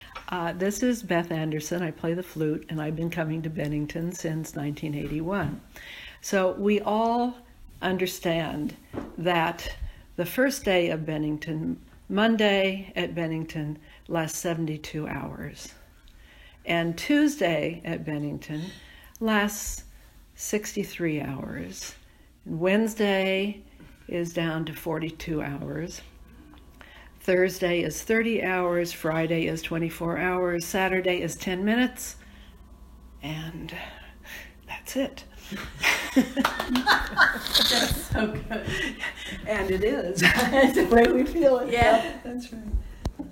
CMC Stories was initiative to collect oral histories from the CMC community.